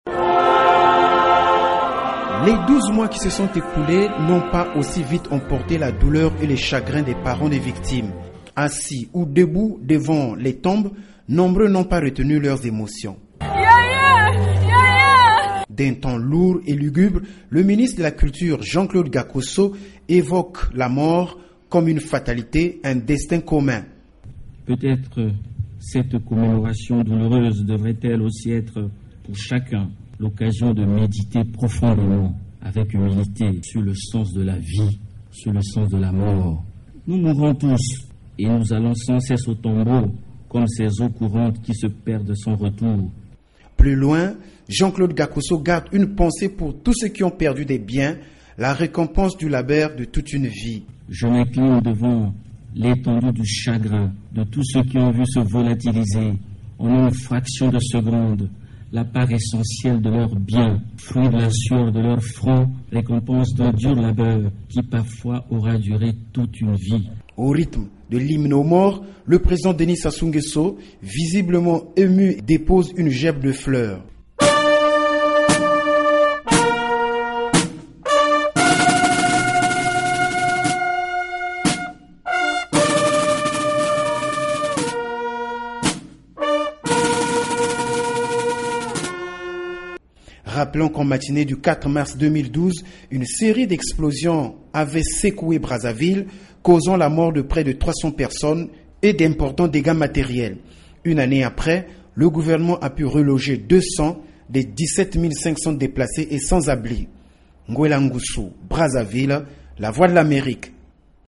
Le reportage de notre correspondant